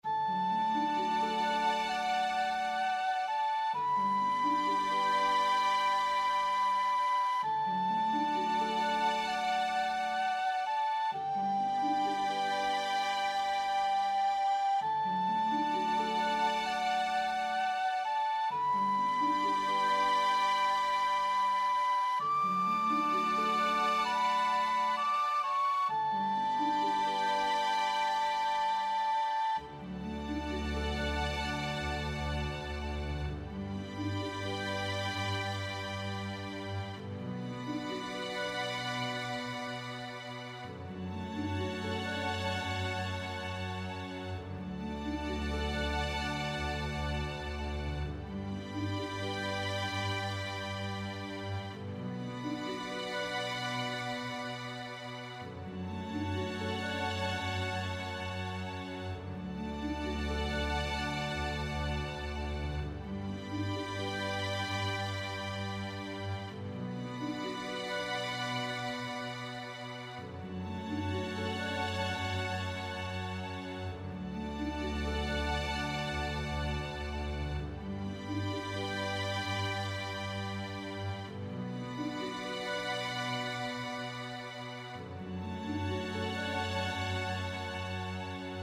Genre: Calming